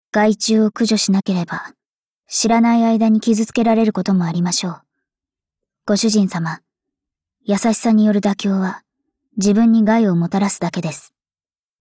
贡献 ） 协议：Copyright，人物： 碧蓝航线:谢菲尔德·META语音 您不可以覆盖此文件。